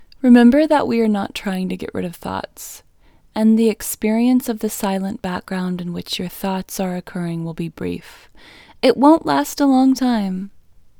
LOCATE OUT English Female 33